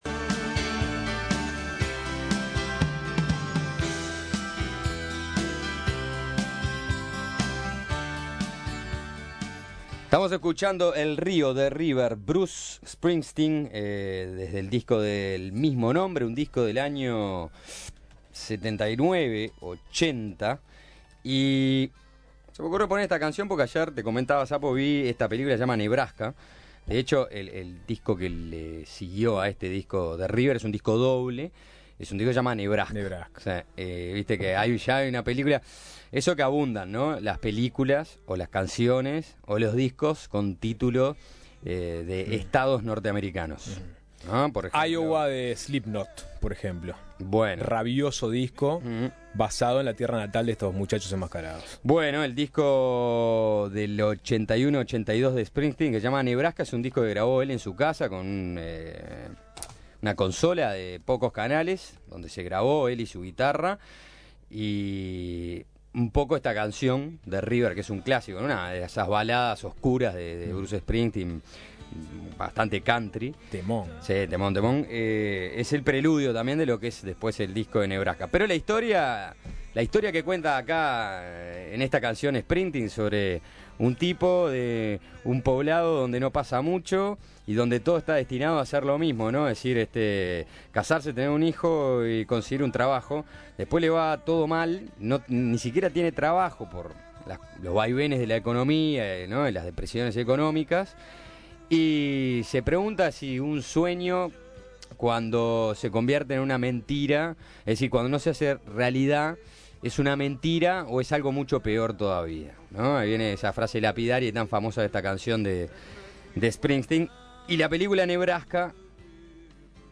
Carolina Ache, abogada y convencional del Partido Colorado, conversó con Suena Tremendo sobre la campaña de la agrupación Vamos Uruguay de blanquear los muros y sobre la política a través del voluntariado.